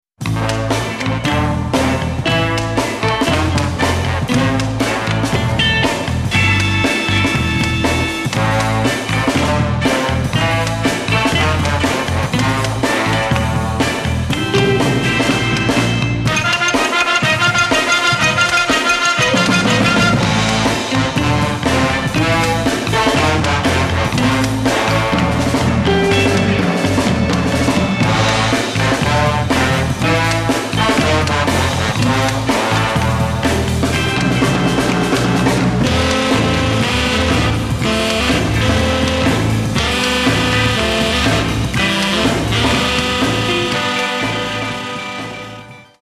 Crime Jazz at its best!